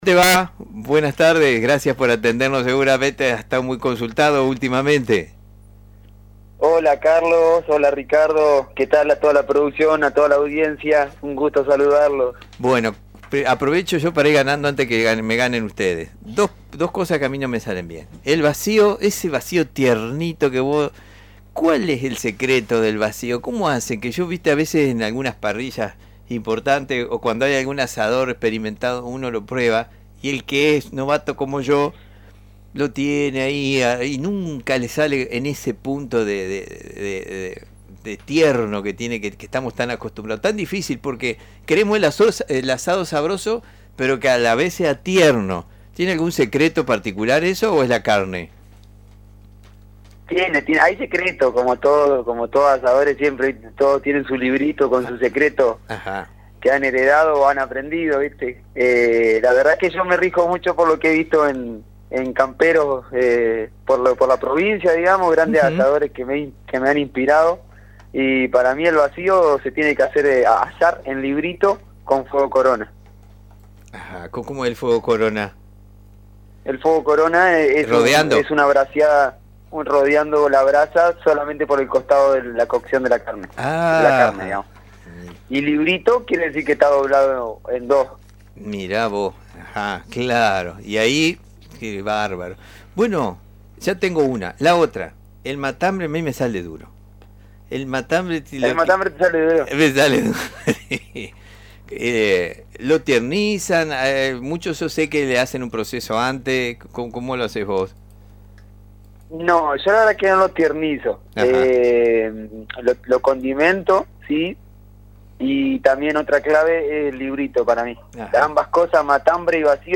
En diálogo con el programa “Puntos Comunes”, de LT 39